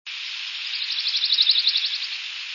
Pine Warbler
Van Campen's Trail, Delaware Water Gap, 4/8/00 (11kb) top of trail near fire road, mixed deciduous/pine forest.
Comments: Trill is monotone trill which increases and then decreases in volume. Sounds like a very fast ovenbird that is trying not to be so loud.  This is a quiet, timid bird with a soft voice even up close.
Peterson: "Song a trill, like that of Chipping Sparrow but looser, more musical, and slower in tempo."
warblerpine324.wav